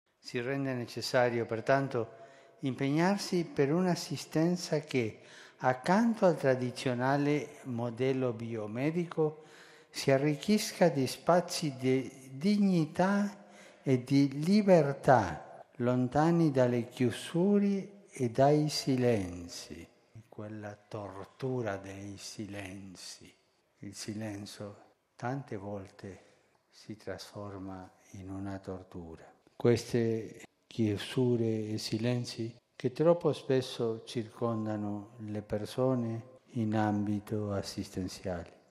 (23.11.2013) På lördagen hölls ett böne- och reflektionsmöte i Paulus VI:s audienshall i Vatikanen där kardinal Zygmunt Zimowski, ordförande i det påvliga rådet för sjukvårdspersonal och ärkebiskop José Rodríguez Carballo, sekreteraren i Vatikanens kongregation för ordenslivet talade innan påven Franciskus kom in till alla troendes glädje.